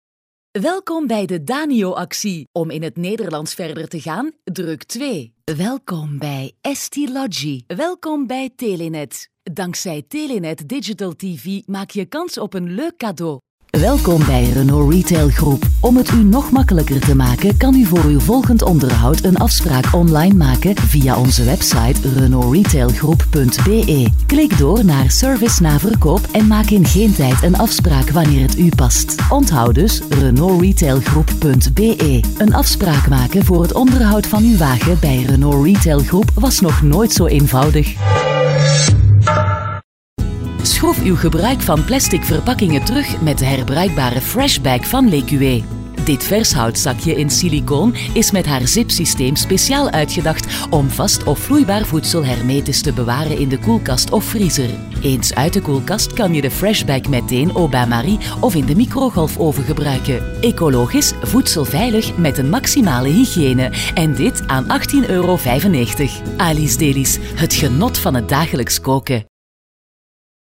Professionelle Sprecher und Sprecherinnen
Belgisch
Weiblich